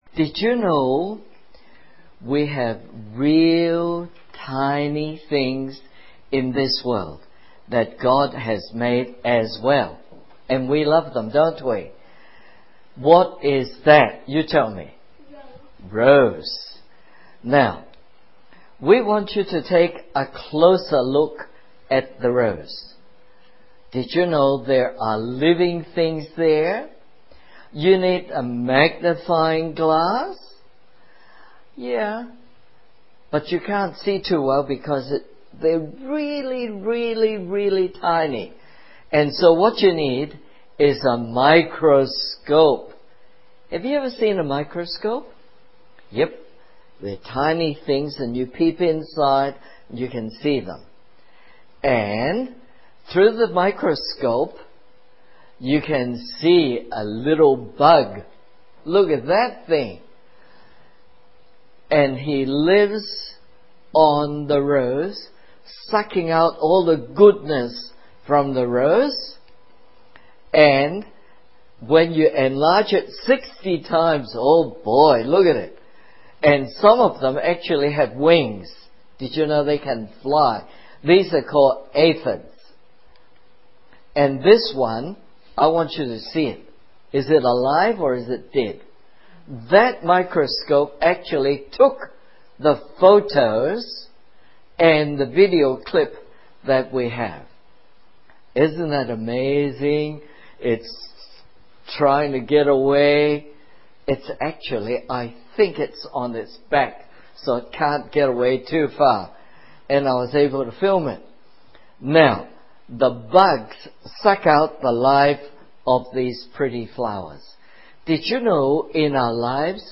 Children's Stories